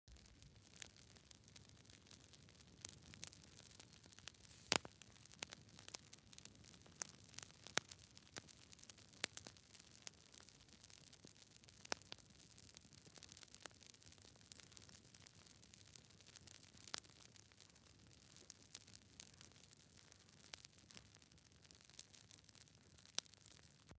دانلود صدای آتش 5 از ساعد نیوز با لینک مستقیم و کیفیت بالا
جلوه های صوتی
برچسب: دانلود آهنگ های افکت صوتی طبیعت و محیط دانلود آلبوم صدای شعله های آتش از افکت صوتی طبیعت و محیط